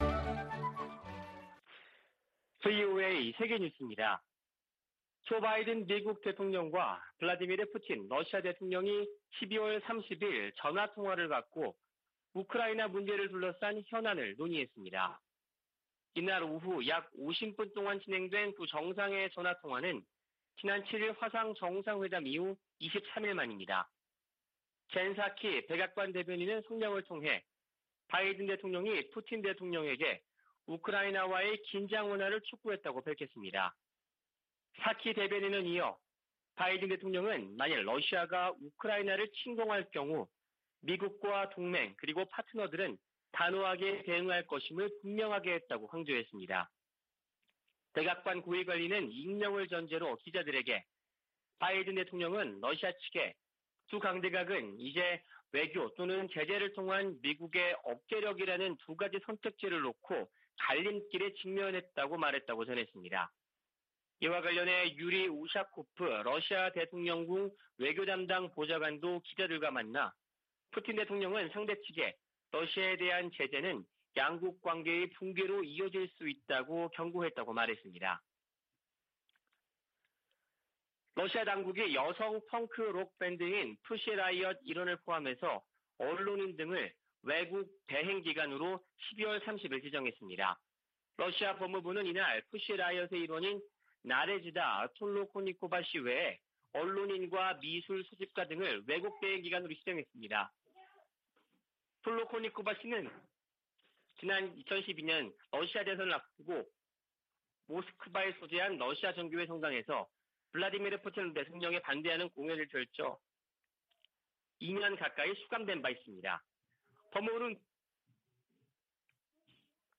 VOA 한국어 아침 뉴스 프로그램 '워싱턴 뉴스 광장' 2022년 1월 1일 방송입니다. 미국과 한국이 종전선언 문안에 합의한 것으로 알려지면서 북한과의 협의로 진전될지 주목됩니다. 2021년에 미국은 7년 만에 가장 적은 독자 대북 제재를 부과했습니다. 2021년 북한의 곡물생산량이 전년보다 증가했지만 식량난은 여전한 것으로 분석됐습니다.